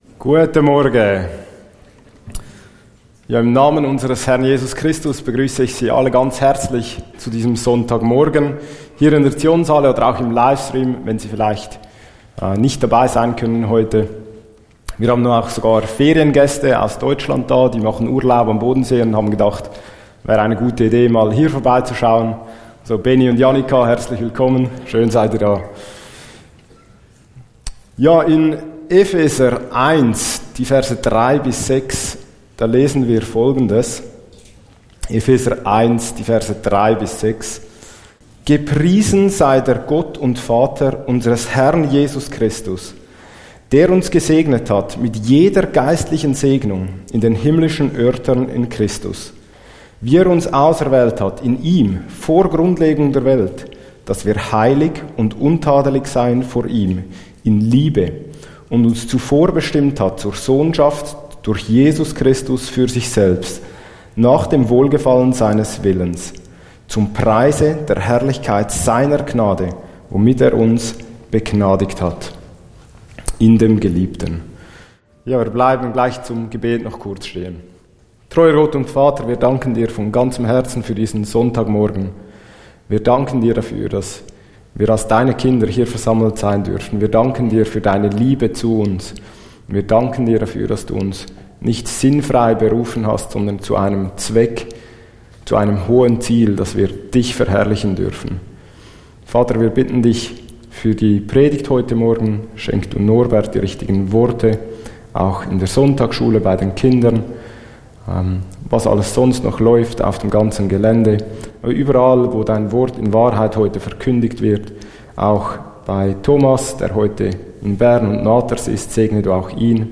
Einleitungen Gottesdienst